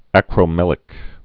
(ăkrō-mĕlĭk)